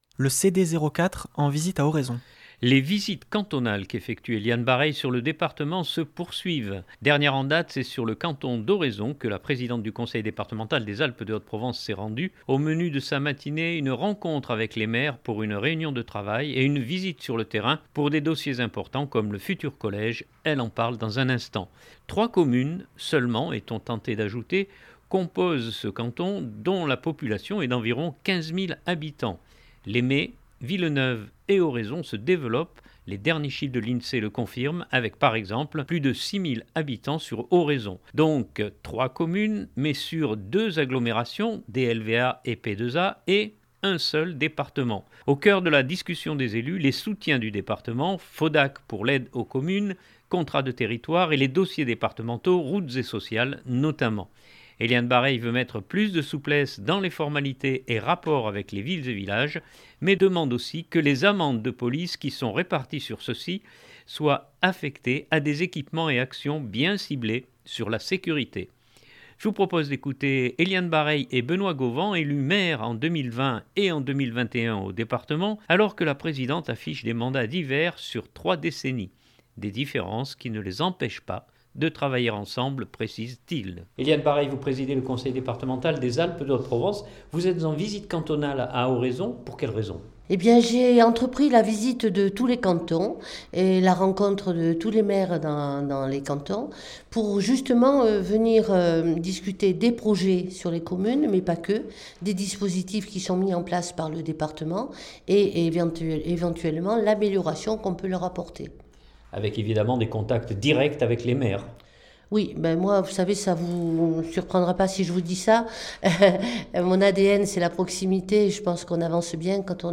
Je vous propose d’écouter Eliane Barreille et Benoît Gauvan élu maire en 2020 et en 2021 au Département alors que la présidente affiche des mandats divers sur trois décennies.